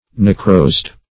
Search Result for " necrosed" : The Collaborative International Dictionary of English v.0.48: Necrosed \Ne*crosed"\ (n[-e]*kr[=o]st"), a. (Med.)